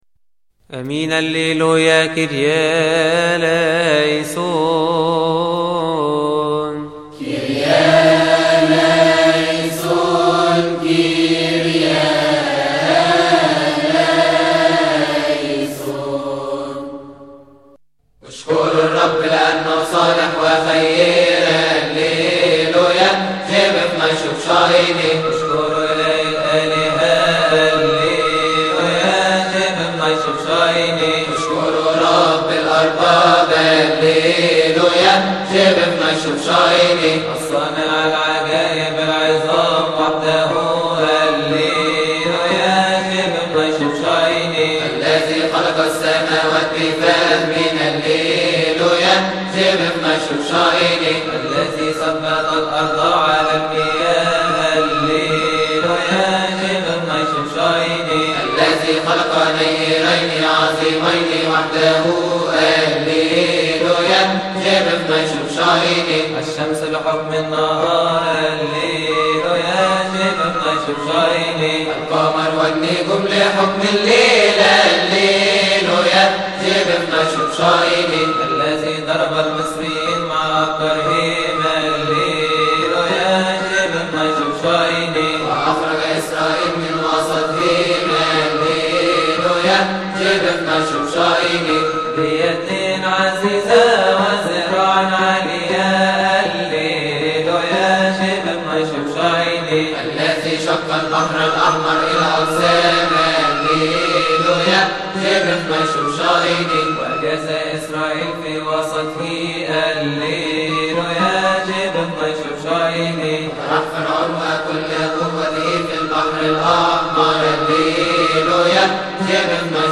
استماع وتحميل لحن الهوس الثانى عربى من مناسبة keahk